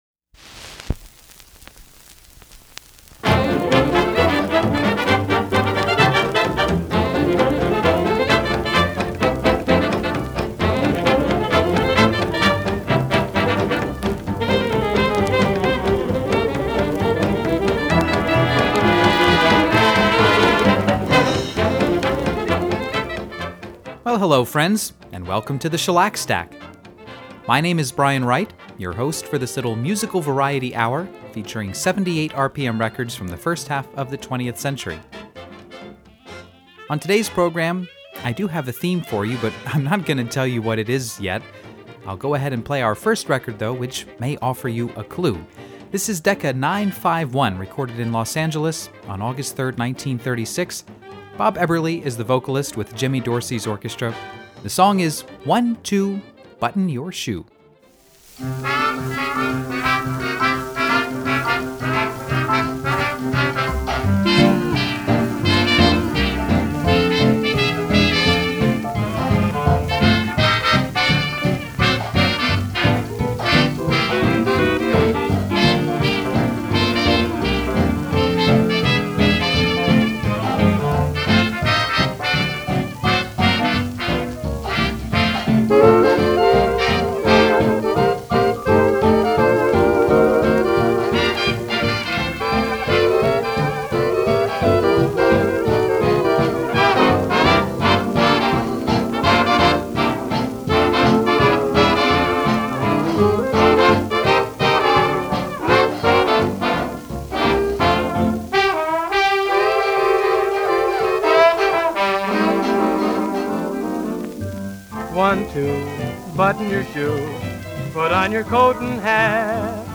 great jazz
solid dance records
“America’s Golden Tenor”